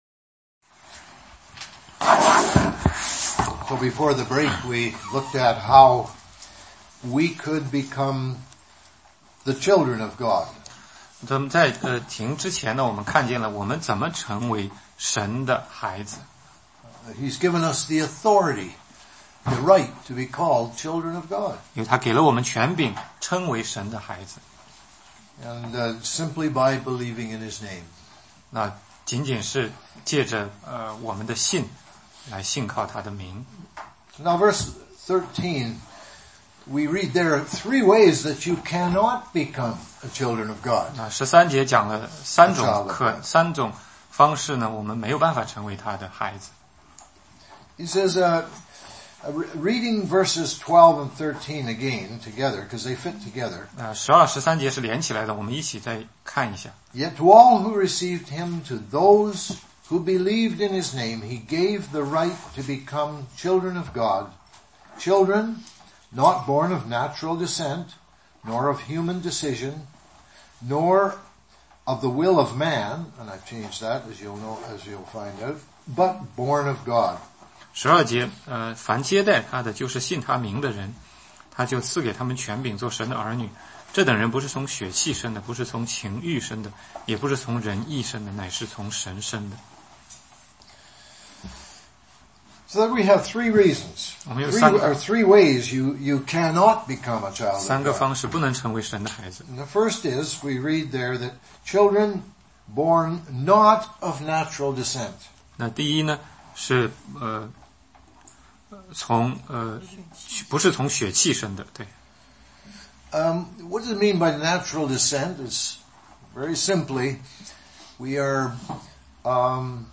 16街讲道录音 - 约翰福音